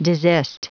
Prononciation du mot desist en anglais (fichier audio)
Prononciation du mot : desist